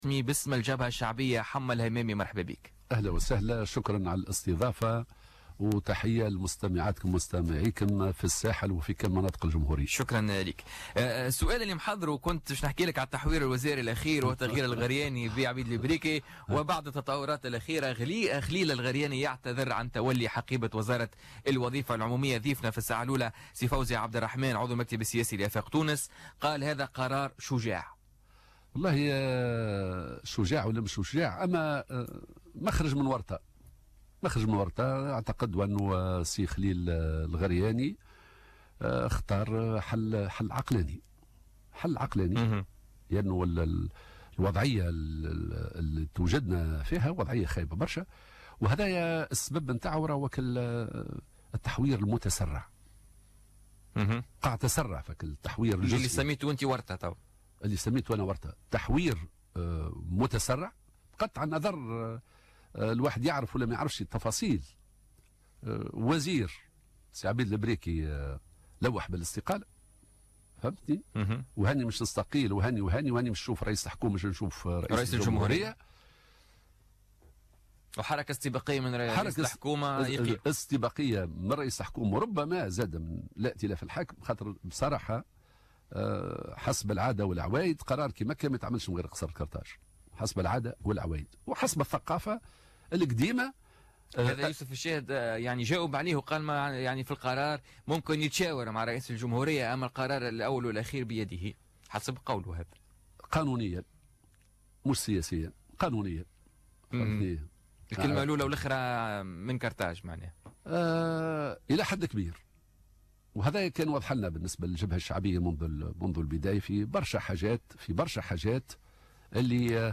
أكد الناطق الرسمي بإسم الجبهة الشعبية حمة الحمامي ضيف بوليتيكا اليوم الخميس 2 مارس 2017 ان اعتذار خليل الغرياني عن منصب وزارة الوظيفة العمومية هو "مخرج من ورطة" مضيفا أنه اختار حلا عقلانيا .